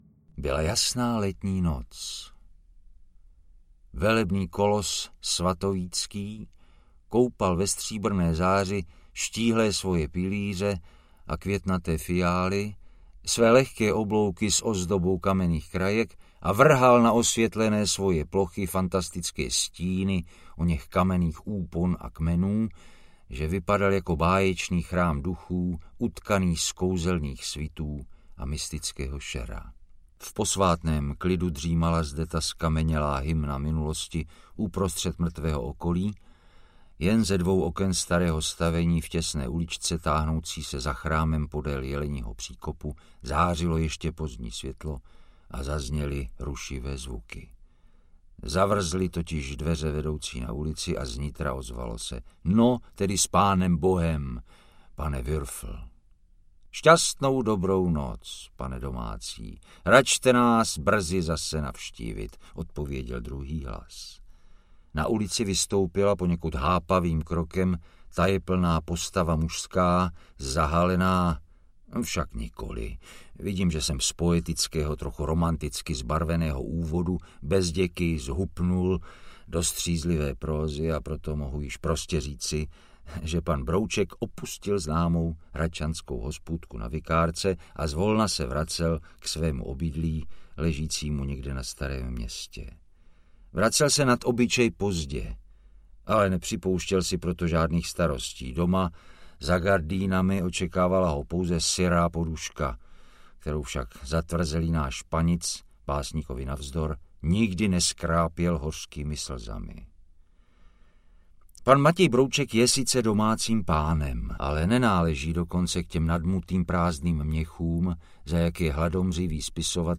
Audio knihaPravý výlet pana Broučka do Měsíce
Ukázka z knihy
• InterpretJiří Štědroň